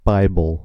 Ääntäminen
IPA : /ˈbaɪbəl/